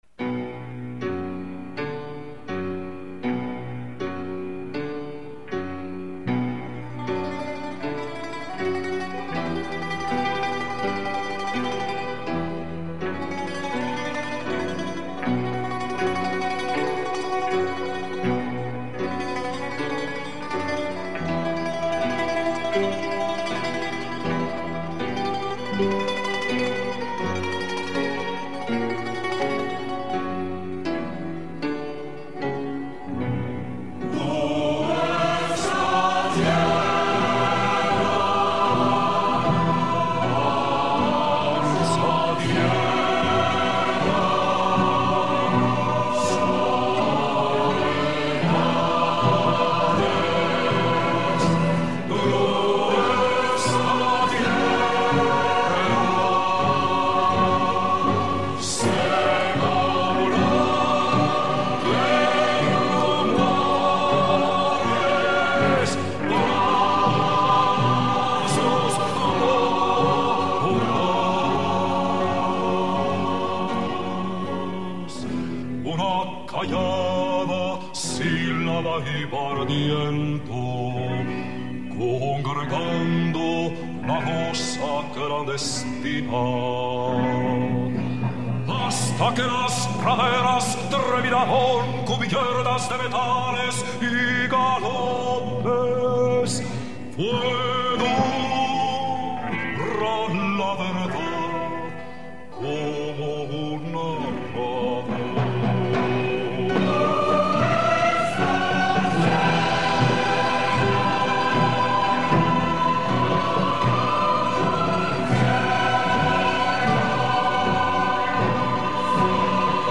Chorale Notre-Dame de Fatima
" Flâneries Musicales " 19 Juin 2005
Choeur commun